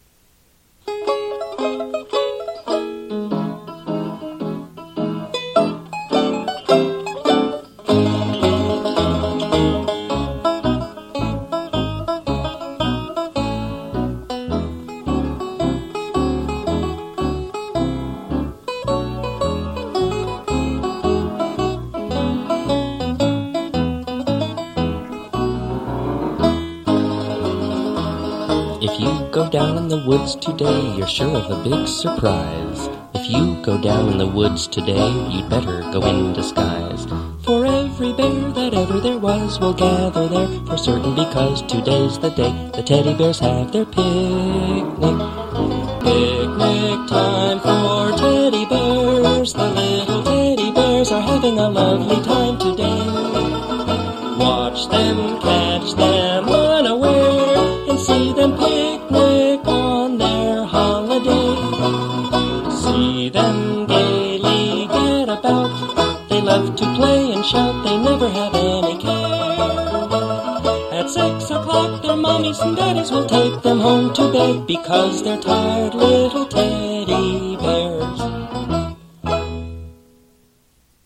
8-beat Intro.
This song is in 6/8 time.